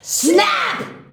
SSSSSSNAP.wav